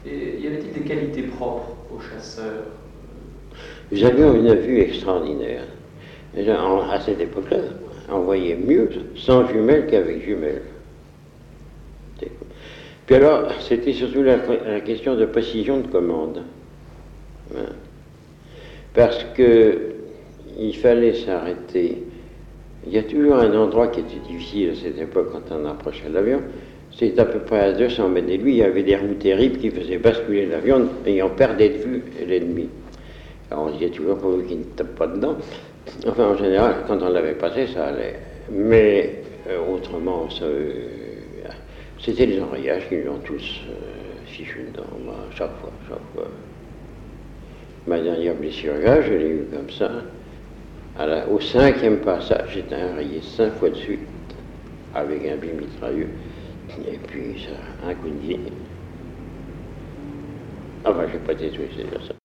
Entretien réalisé le 26 janvier 1976 à Chantilly (Oise)